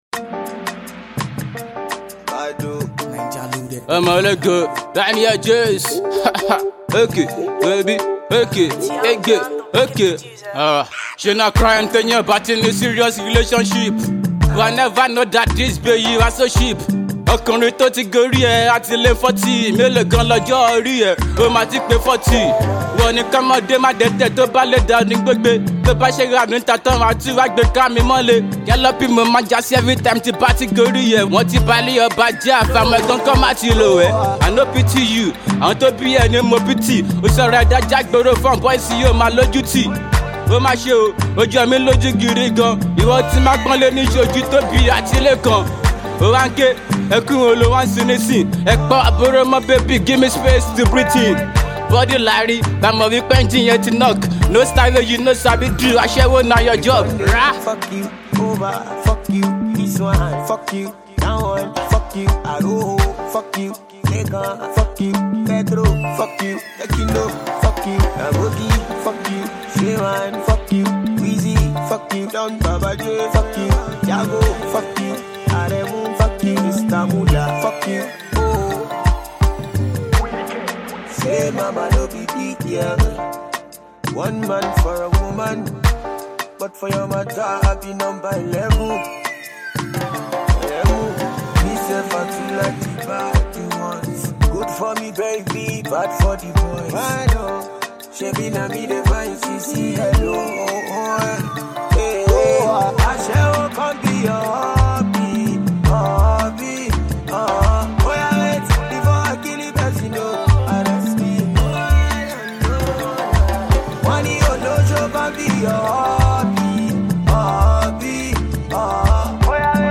Indigenous rapper